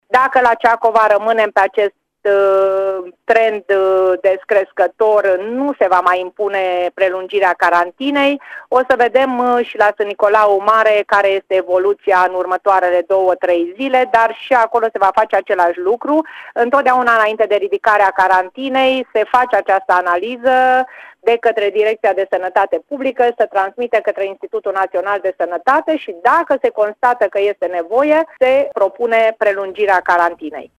Prefectul s-a mai referit, în direct la Radio Timișoara, la situația celor două orașe aflate în carantină: Ciacova unde rata de infectare a scăzut și Sânnicolau Mare – unde a crescut.